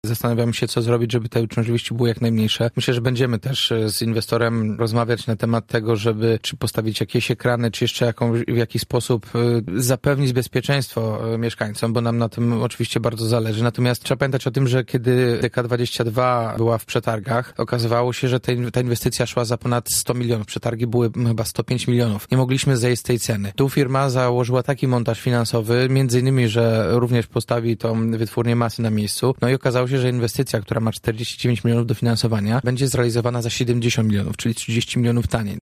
Prezydent Gorzowa Jacek Wójcicki, który był dziś porannym gościem Radia Zachód, mówi, że magistrat doskonale zna ten problem.